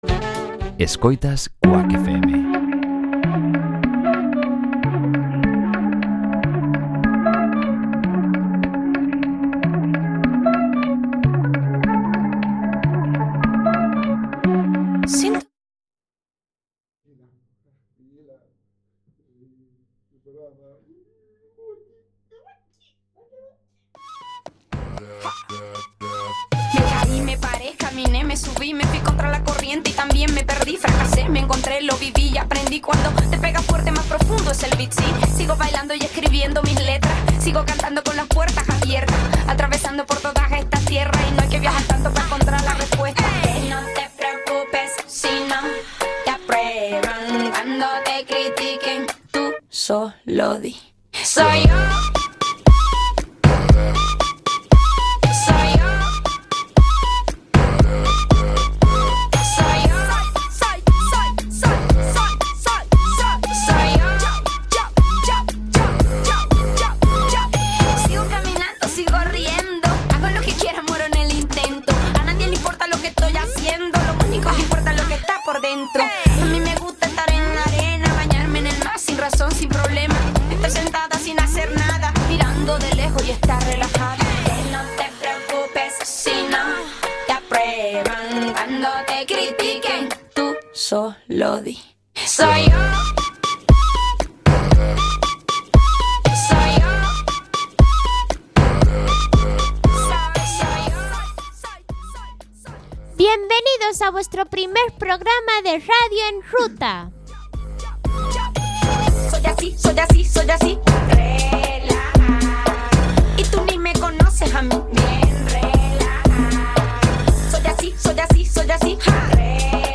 Radio en Ruta é o magazine radiofónico feito polo alumnado do programa formativo Ruta, unha formación dirixida a mozos e mozas con discapacidade intelectual da Fundación Amador de Castro.